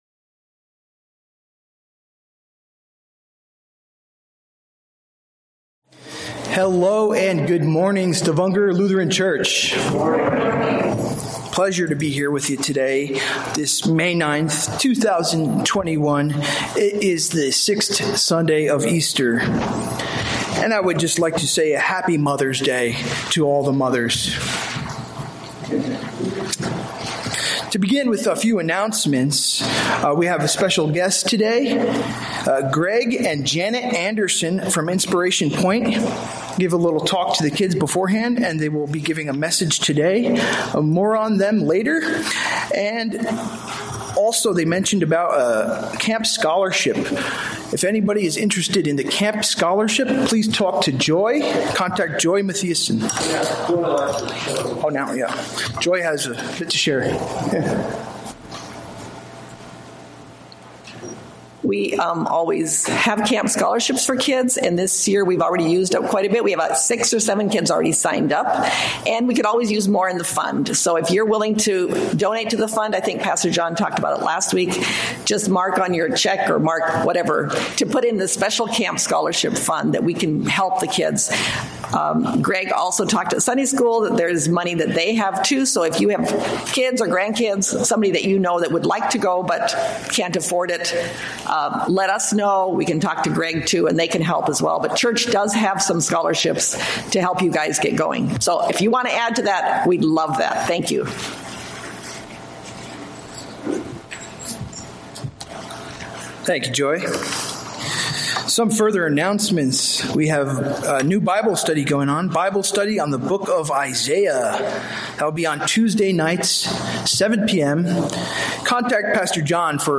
From Series: "Sunday Worship"